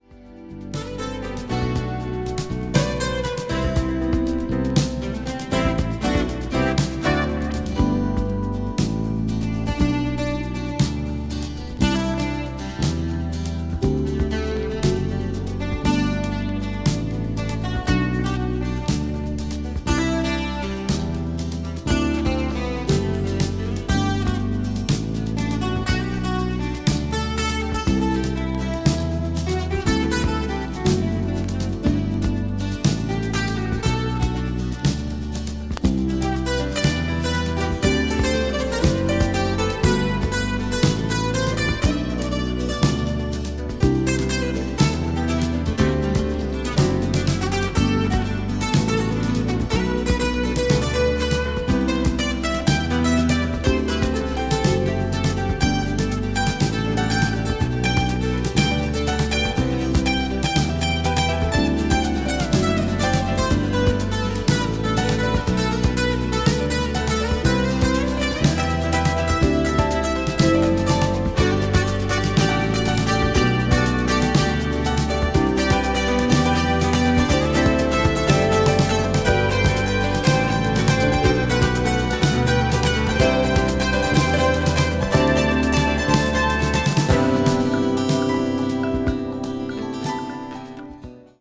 piano
drums
bass
percussion
a mystical and transparent crossover sound
acoustic   ambient   balearic   crossover   new age